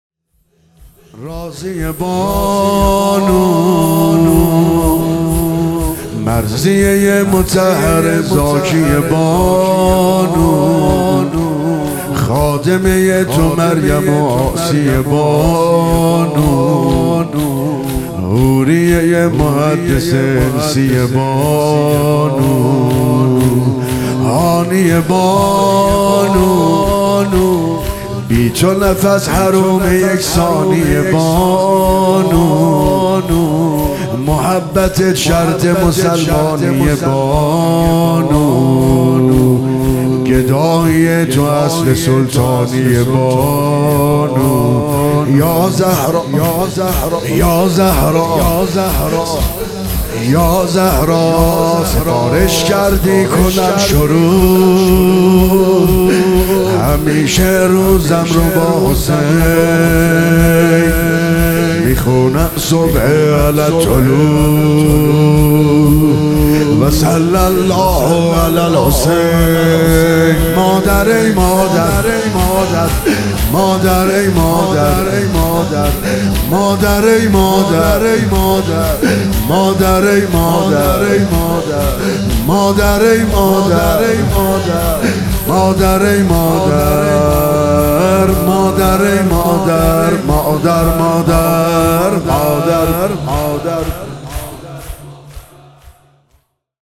راضیه بانو مرضیه مطهره زاکیه بانو - شور
هیئت هفتگی